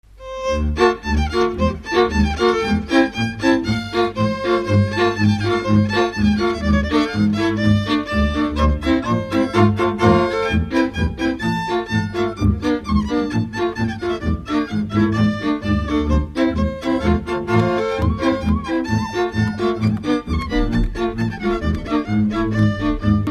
Dallampélda: Hangszeres felvétel
Dunántúl - Somogy vm. - Berzence
hegedű
kontra
bőgő
Műfaj: Kanásztánc
Stílus: 7. Régies kisambitusú dallamok
Kadencia: VII (1) 5 1